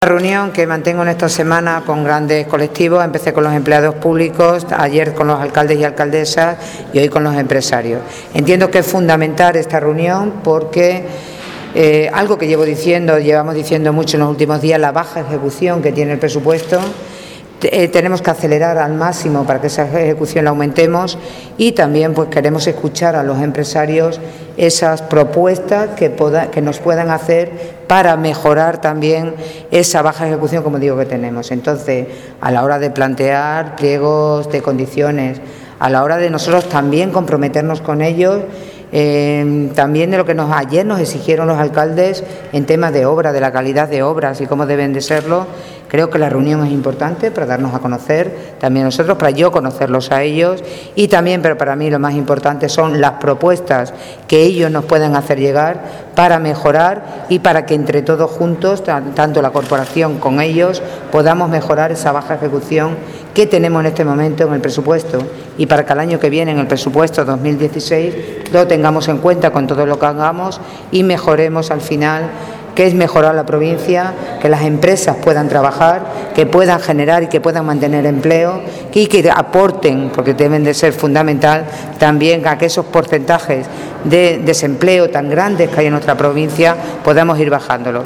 CORTES DE VOZ
Este ha sido el mensaje fundamental que Charo Cordero, Presidenta de la Diputación Provincial de Cáceres, ha subrayado en la reunión que ha tenido con los empresarios de la provincia.